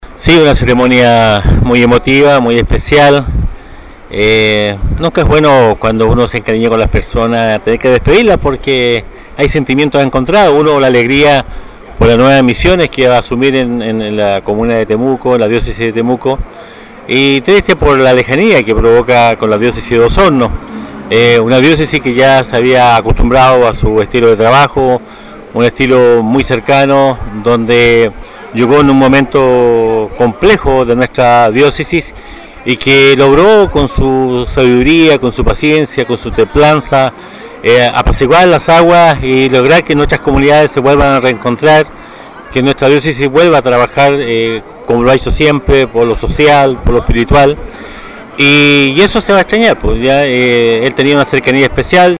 Con la Iglesia Catedral San Mateo completa de fieles, en la tarde de ayer se realizó la Misa-Acción de Gracias por su servicio como pastor de la Diócesisde Monseñor Jorge Concha Cayuqueo.
El alcalde de Osorno, Emeterio Carrillo -quien si bien no estaba en el programa., fue incluido para despedir al Obispo a nombre de los habitantes de Osorno, acción donde señaló lamentar lo rápido que pasó el tiempo, y lo pronto que fue trasladado a Temuco-